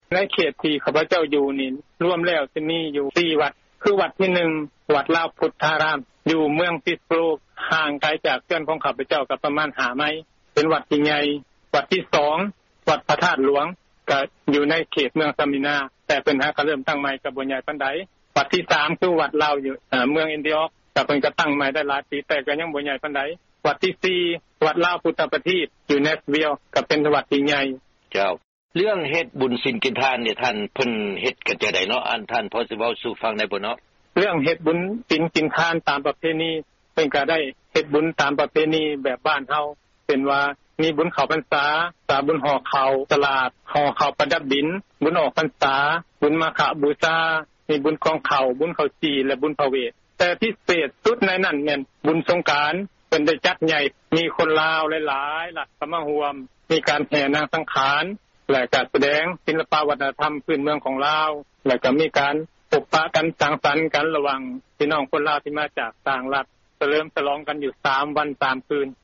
ທີ່ທ່ານຫາກໍໄດ້ຮັບຟັງຜ່ານໄປນັ້ນ ແມ່ນການໂອ້ລົມກັບຄອບຄົວຄົນລາວ ໃນເຂດເມືອງສເມີນາ ລັດເທັນເນັສຊີ.